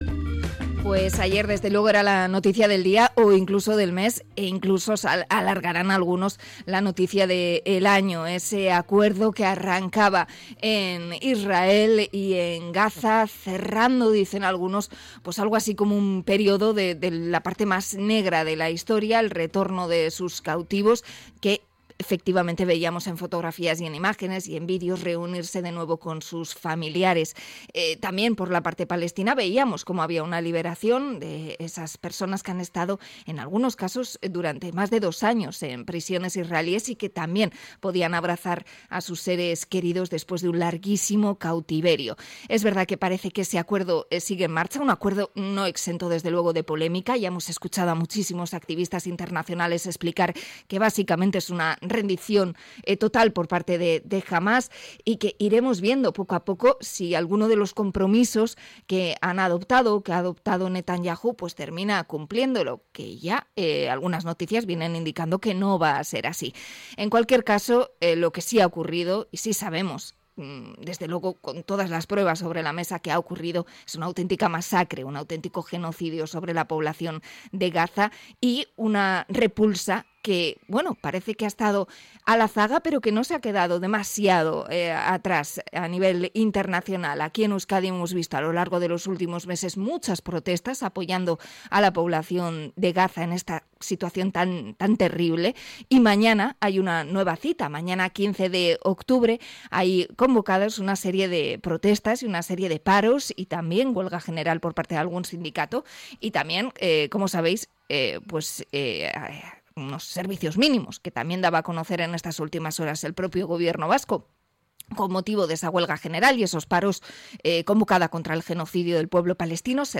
Entrevista a CCOO Euskadi por los paros convocados para el 15 de octubre